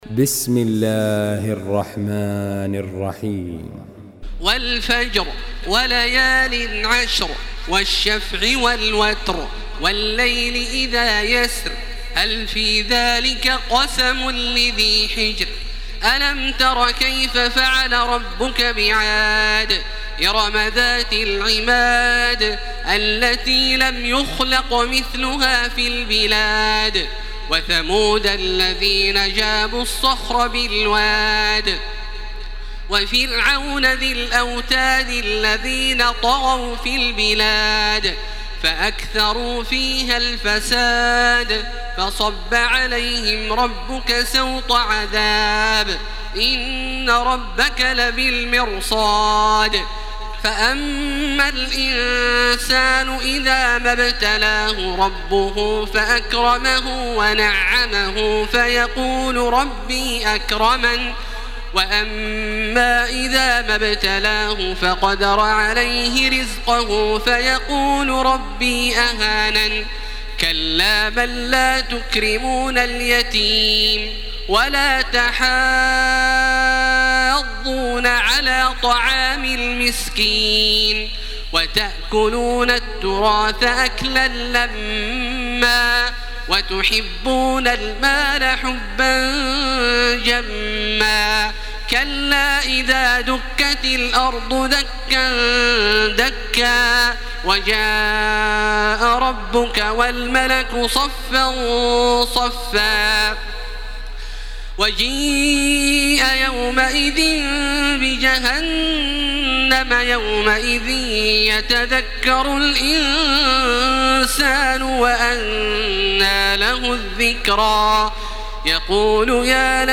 Surah Al-Fajr MP3 by Makkah Taraweeh 1434 in Hafs An Asim narration.
Murattal Hafs An Asim